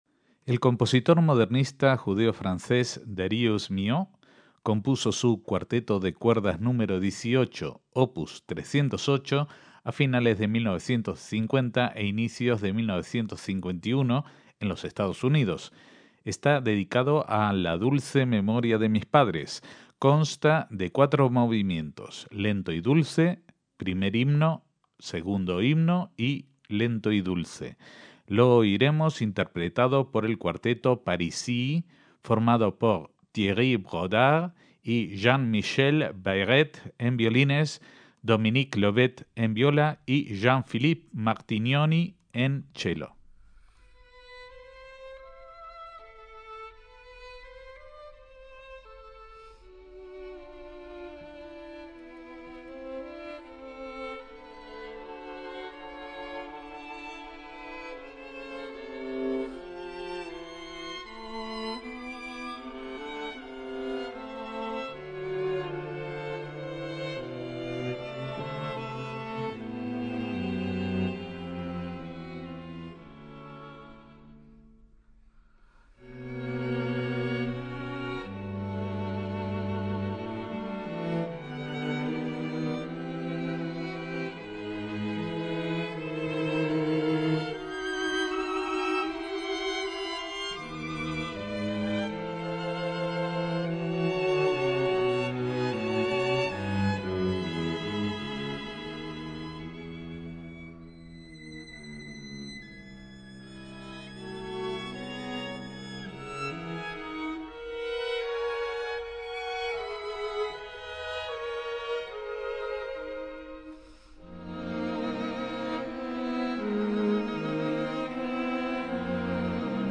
MÚSICA CLÁSICA